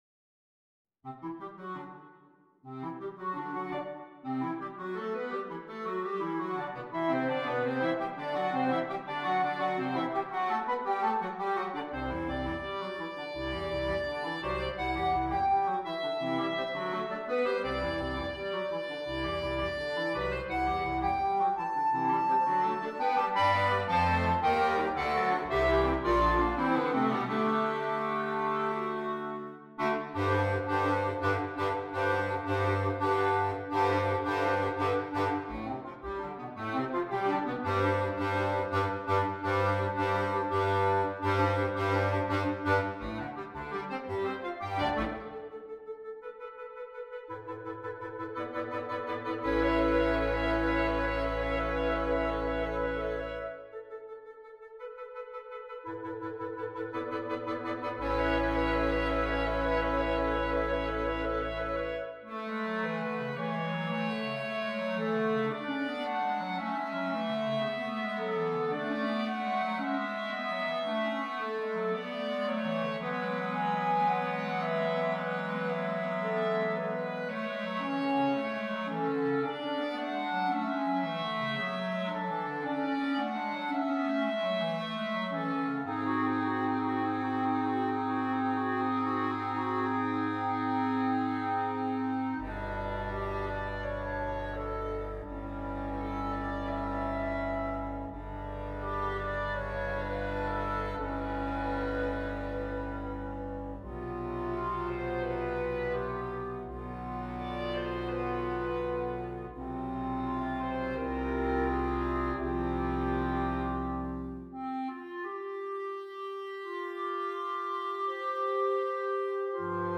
Soprano Clarinet, 4 Clarinets, Bass Clarinet